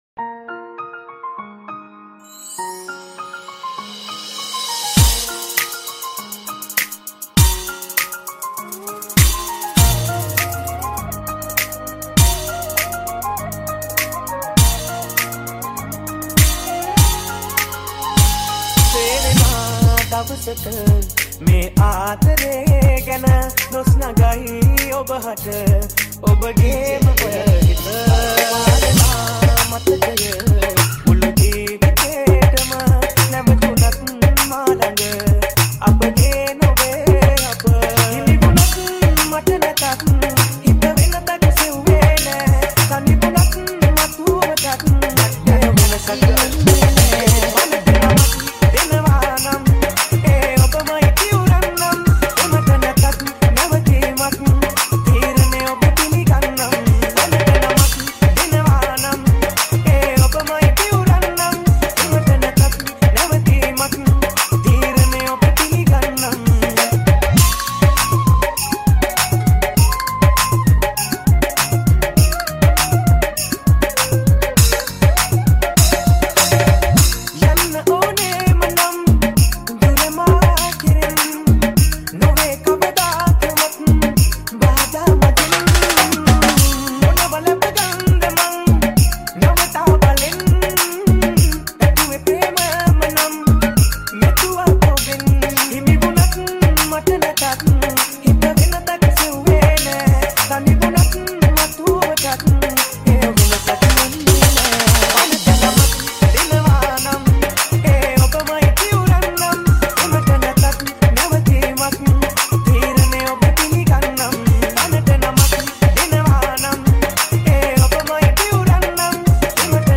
New Sinhala Song